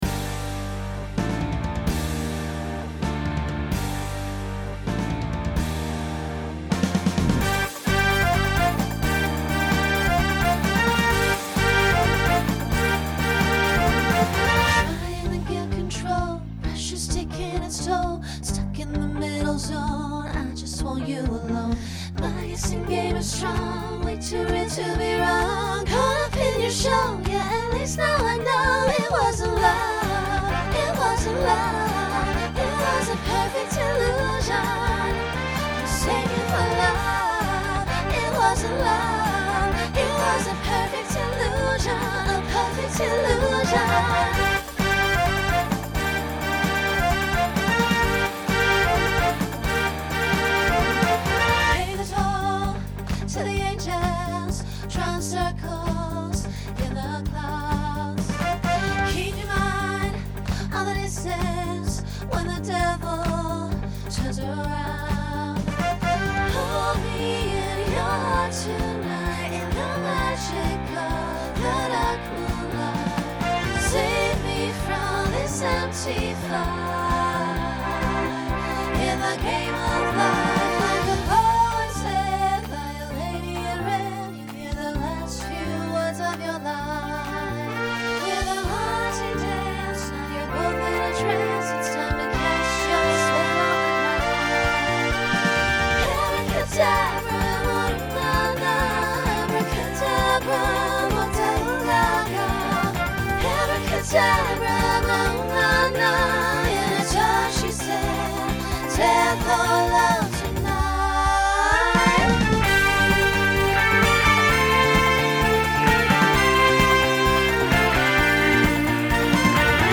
Pop/Dance Instrumental combo
Voicing SSA